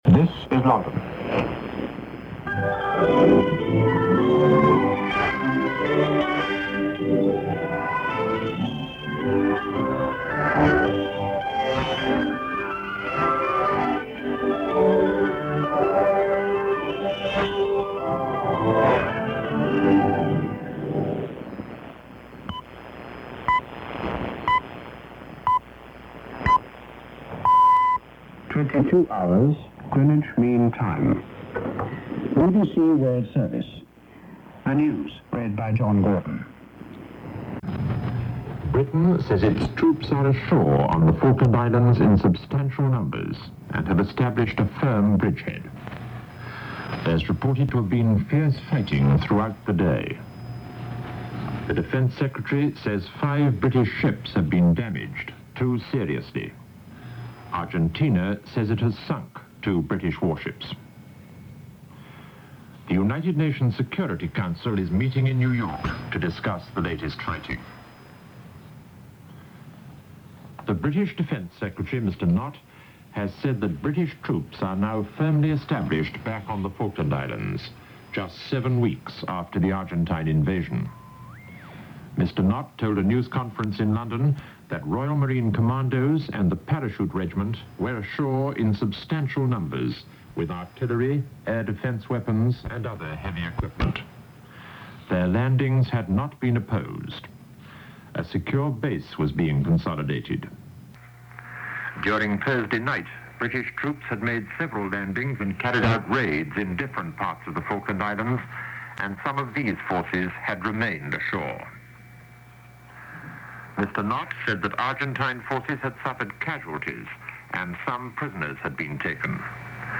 BBC shortwave newscast during the Falklands War reporting that British troops have landed on the Falklands Island.
RX location: Plymouth, Minnesota Receiver and antenna: Hammarlund HQ-180, longwire.